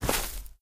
dc0f4c9042 Divergent / mods / Soundscape Overhaul / gamedata / sounds / material / human / step / tmp_default1.ogg 19 KiB (Stored with Git LFS) Raw History